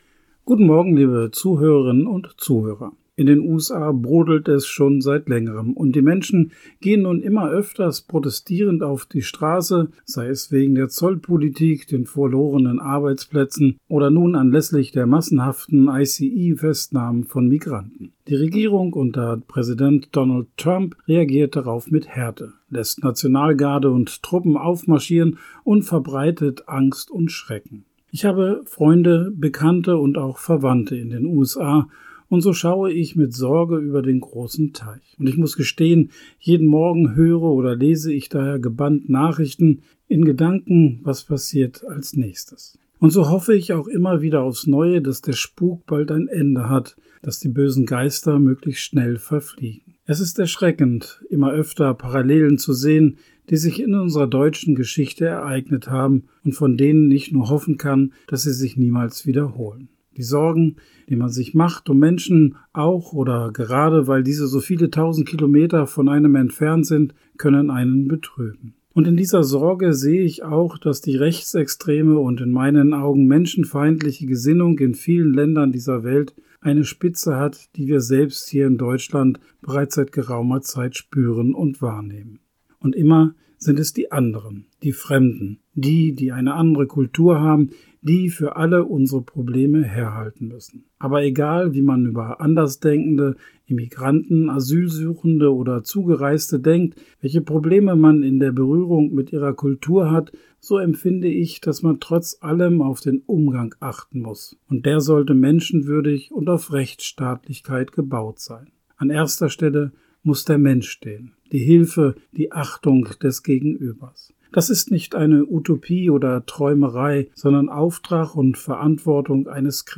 Radioandacht vom 12. Juni